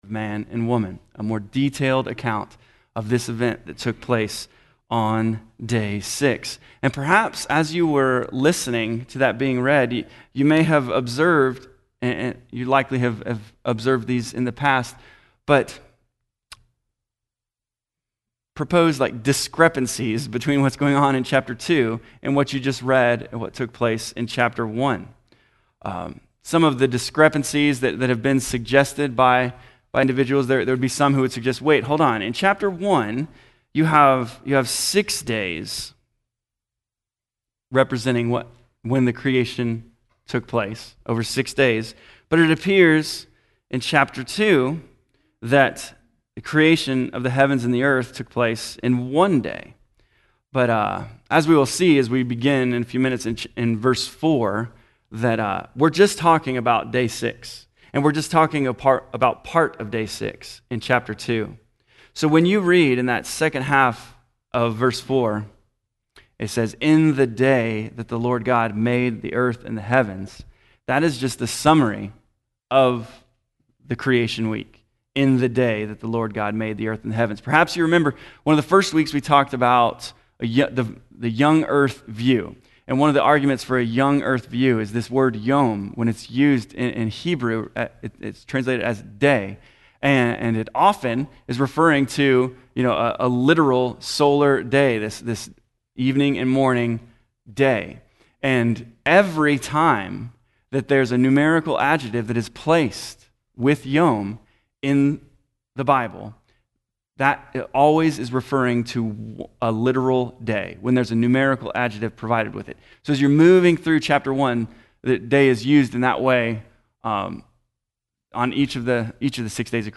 Lesson 10 in the Genesis: Foundations Sunday School class.
Unfortunately, this recording does not include the beginning of the lesson and starts about 10 minutes late.